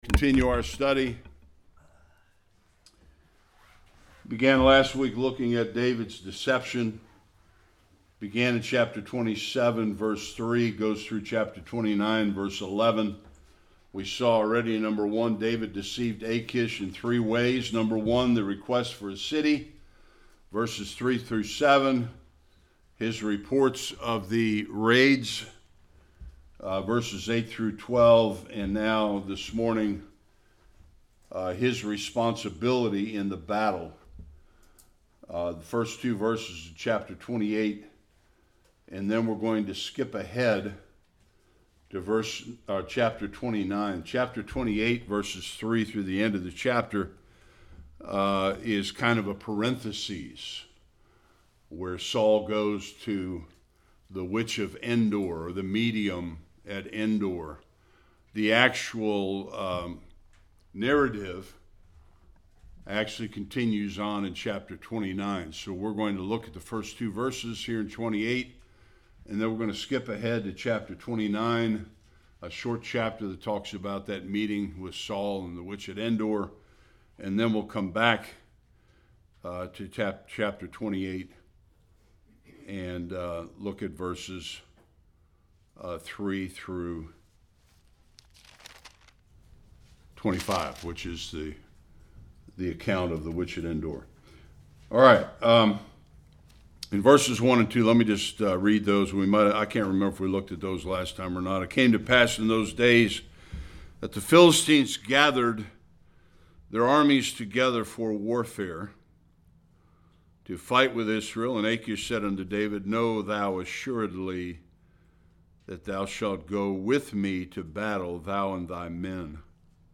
1-11 Service Type: Sunday School Achish sends David back to Ziklag.